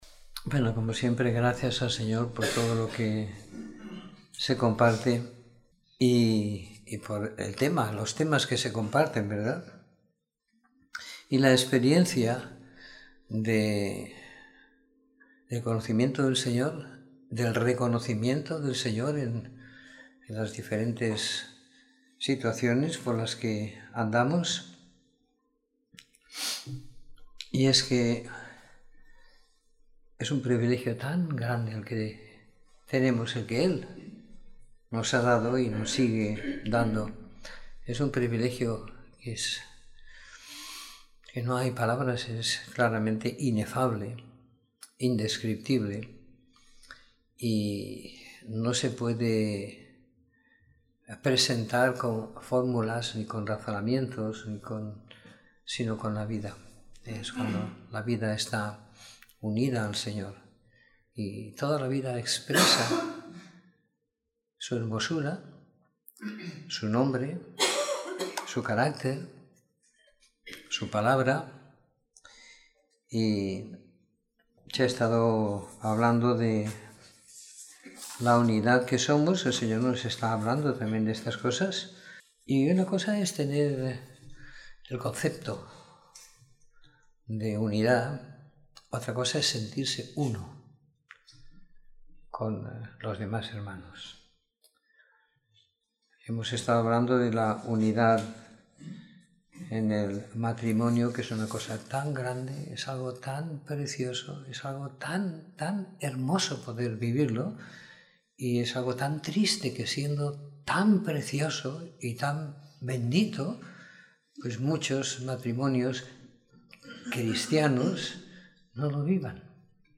Domingo por la Tarde . 26 de Febrero de 2017